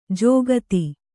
♪ jōgati